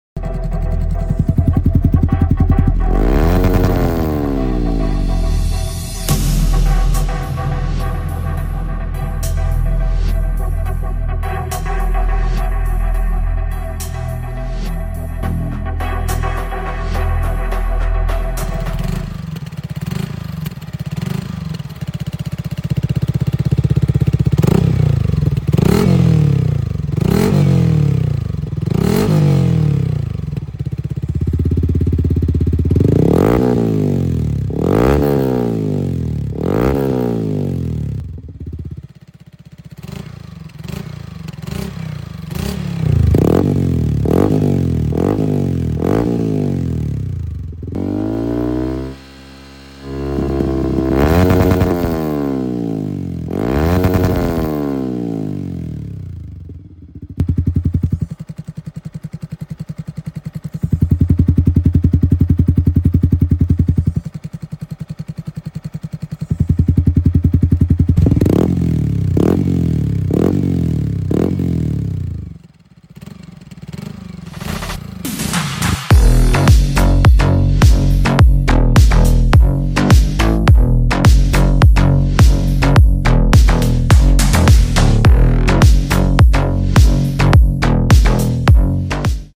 Tridente f23 installed on Yamaha